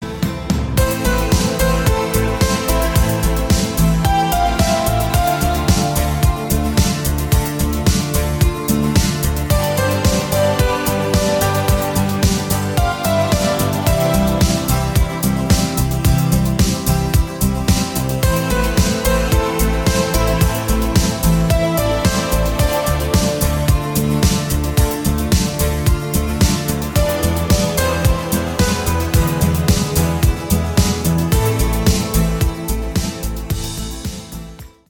• Качество: 192, Stereo
спокойные
без слов
клавишные
инструментальные